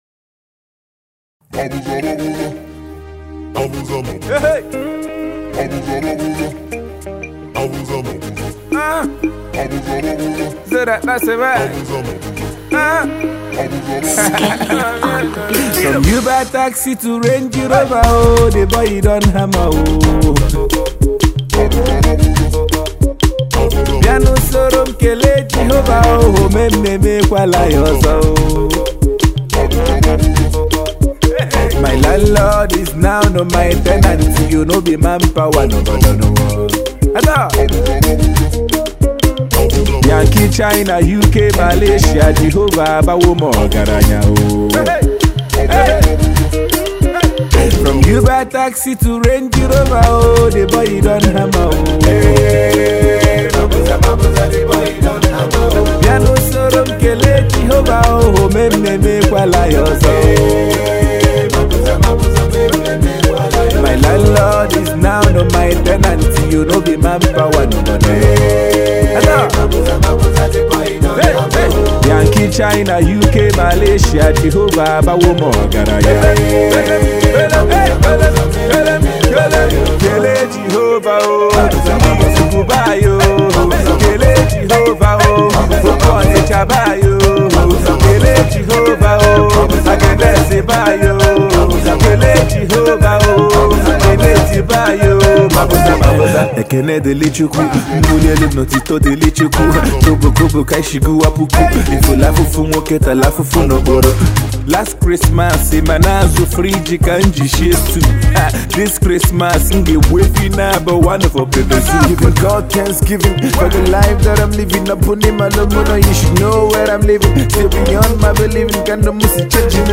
guitar strings